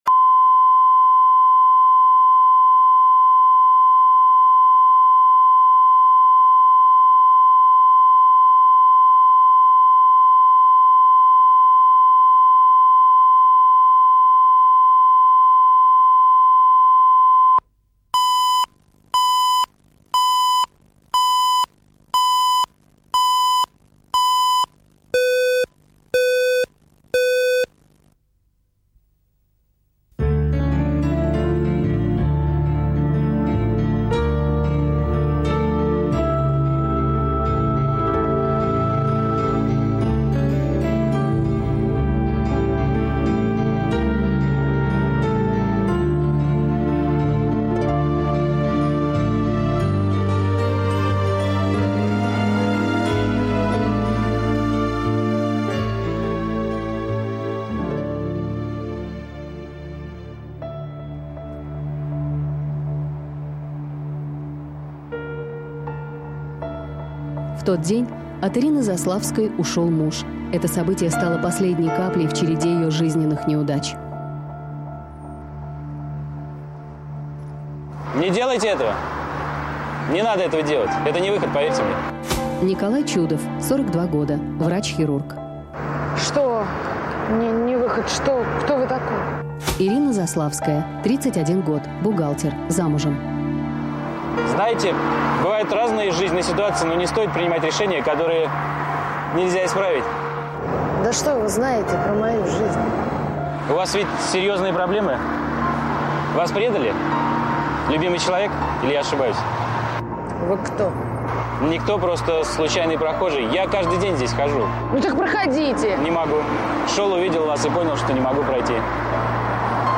Аудиокнига Человек на мосту | Библиотека аудиокниг
Aудиокнига Человек на мосту Автор Александр Левин.